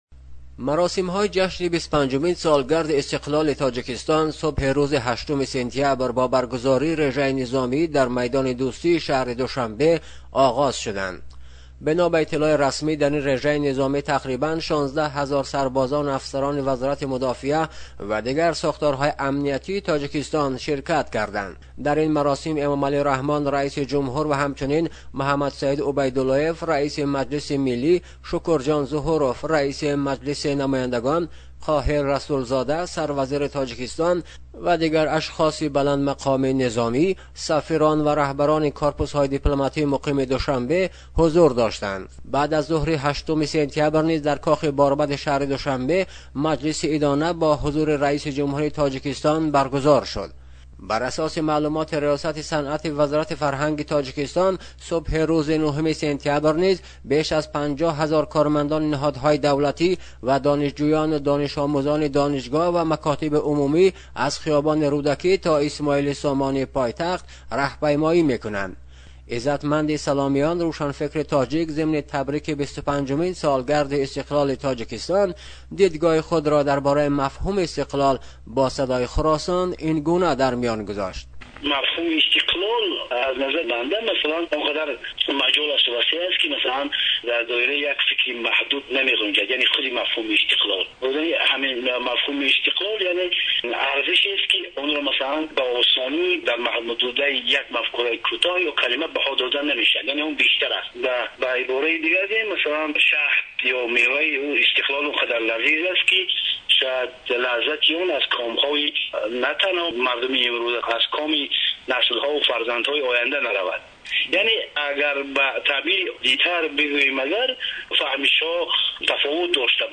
گزارش می دهد.